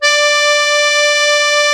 MUSETTE 1.11.wav